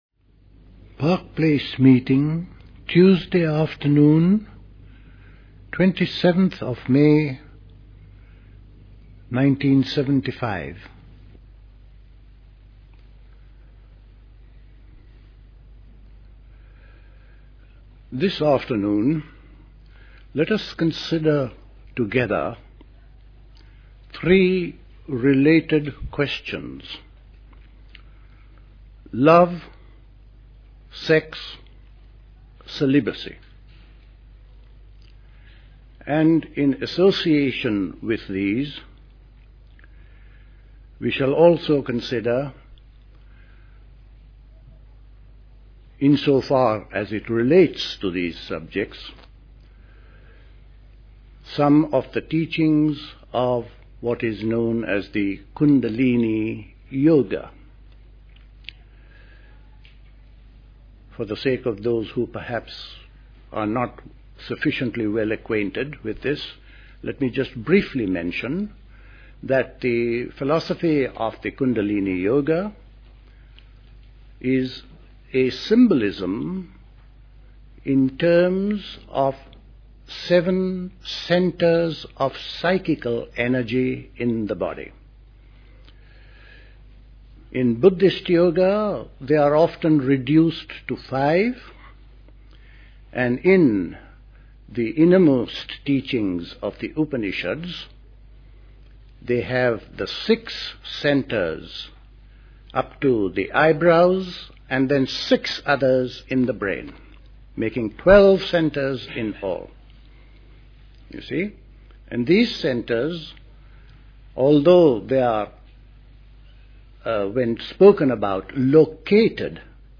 Recorded at the 1975 Park Place Summer School.